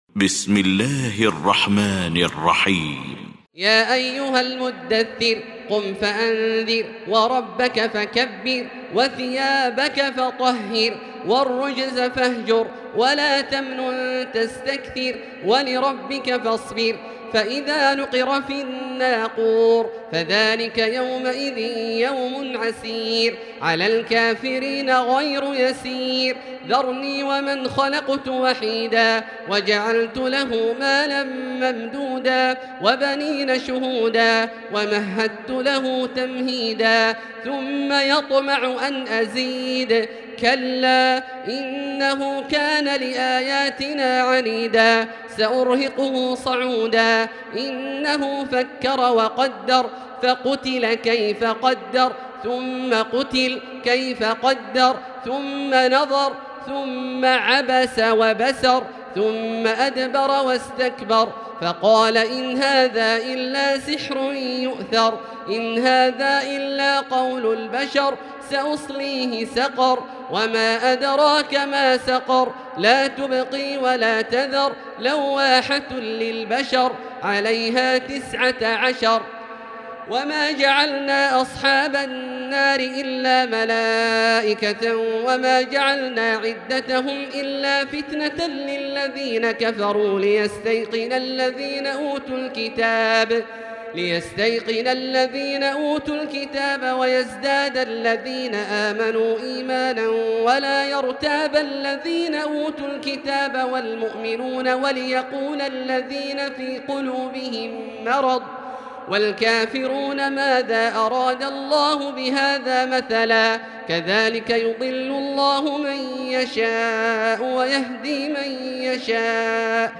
المكان: المسجد الحرام الشيخ: فضيلة الشيخ عبدالله الجهني فضيلة الشيخ عبدالله الجهني المدثر